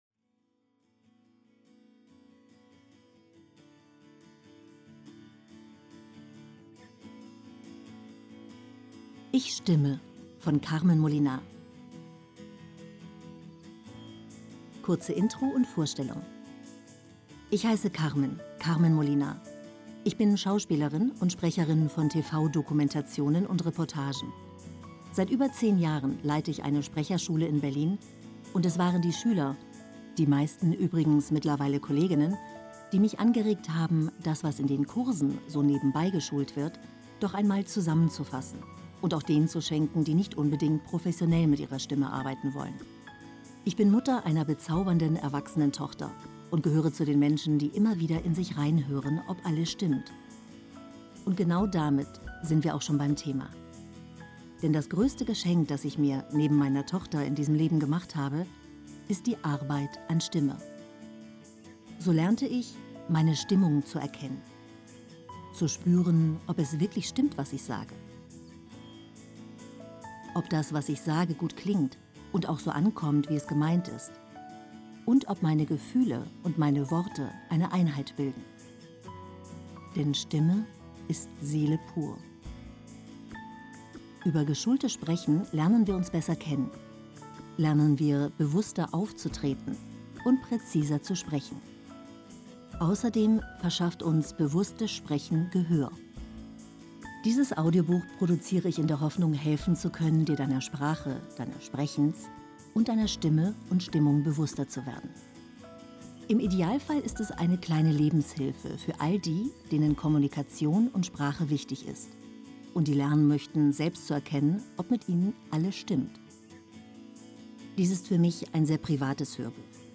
Dieses Hörbuch ist eine Liebeserklärung an die deutsche Sprache.